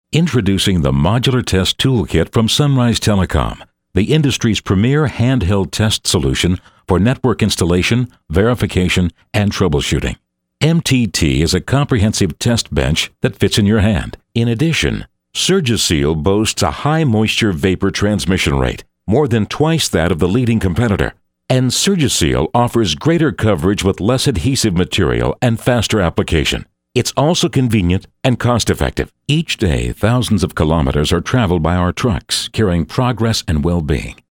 Male American V.O. talent. Warm, Deep, Big, Smooth
Sprechprobe: Industrie (Muttersprache):